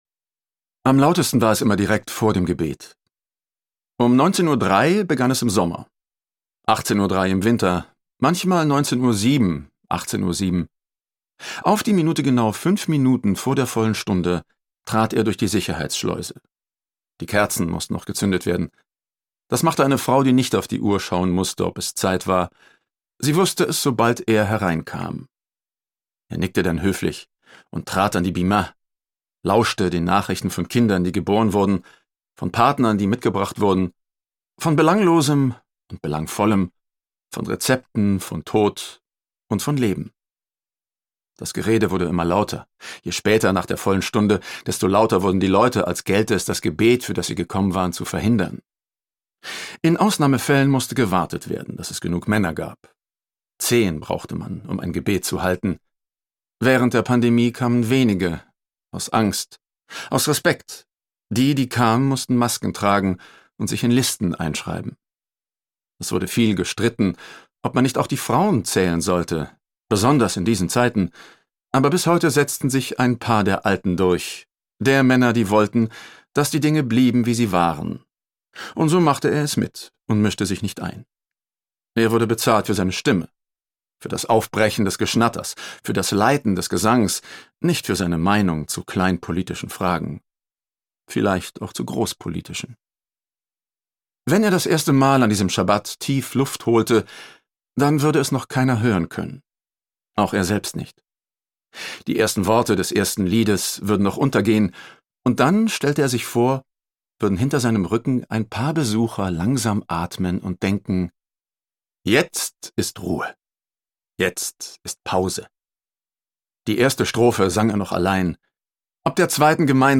Themenwelt Literatur Romane / Erzählungen